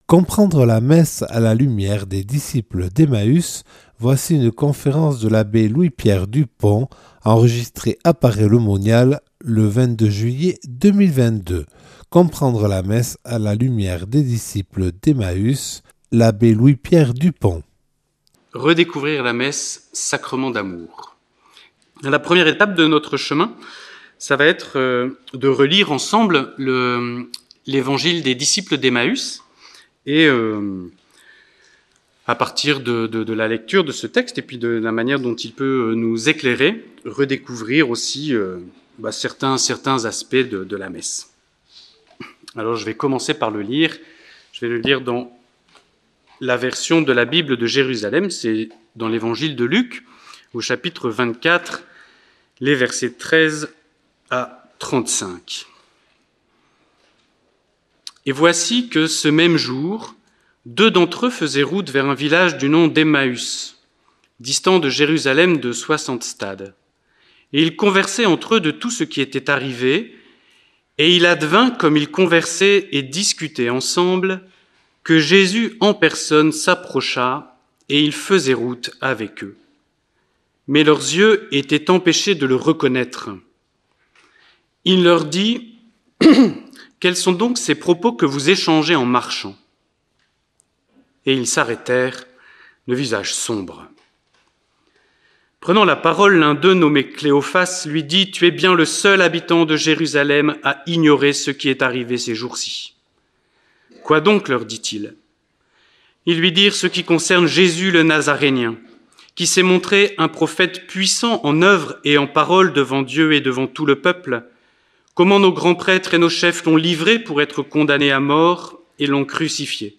Une conférence
(Enregistré le 22/07/2022 à Paray-le-Monial)